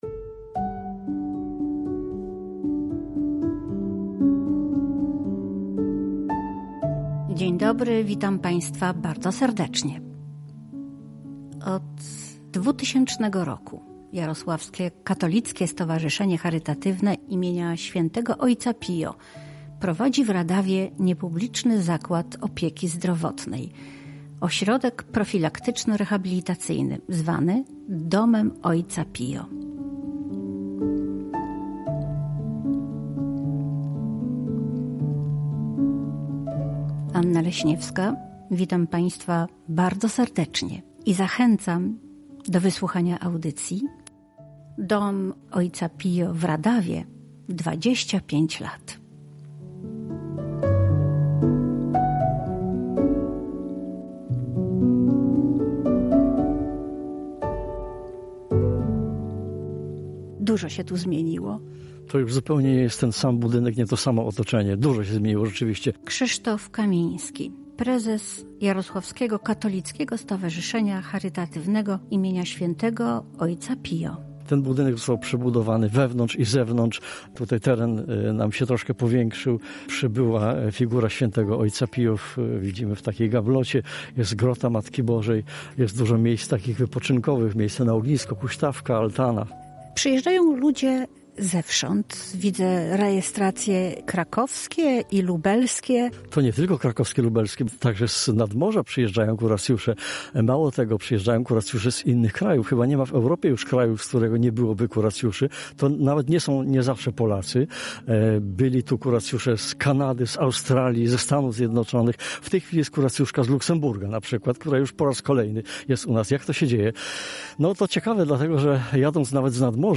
Dom Ojca Pio w Radawie od 25 lat. Reportaż